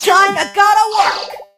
jackie_drill_start_vo_02.ogg